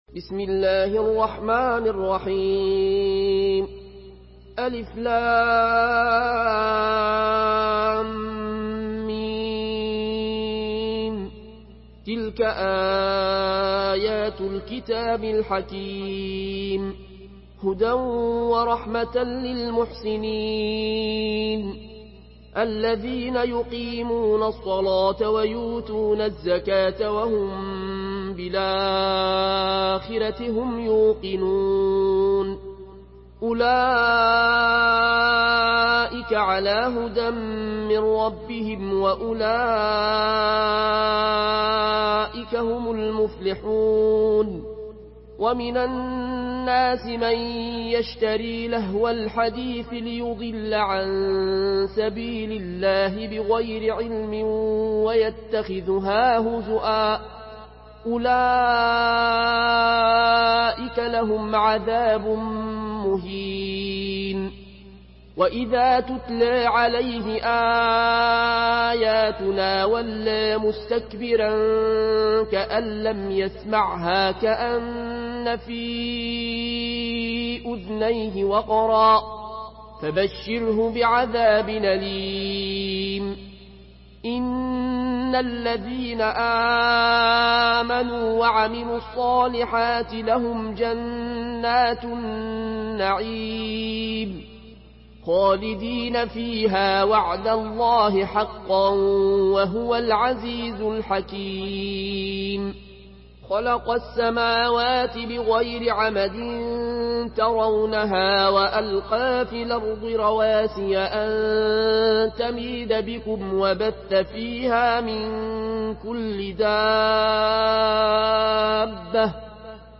Une récitation touchante et belle des versets coraniques par la narration Warsh An Nafi From Al-Azraq way.
Murattal